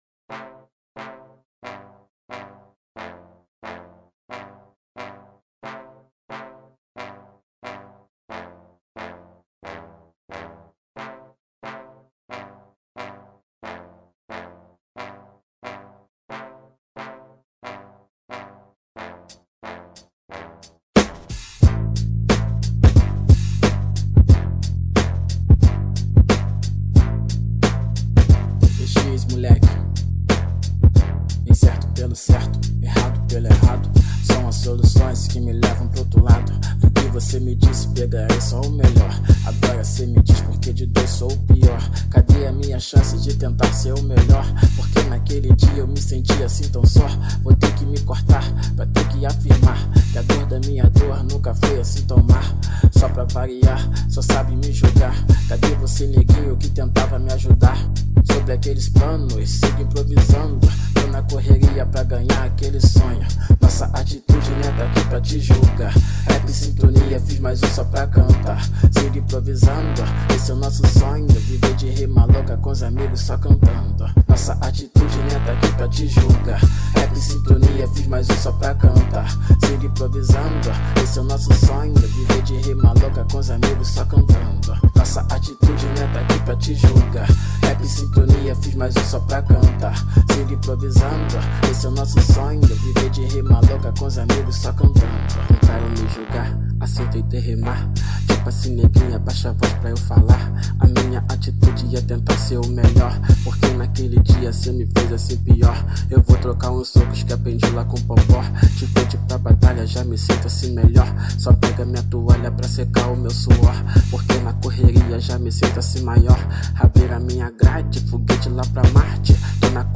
Rap Nacional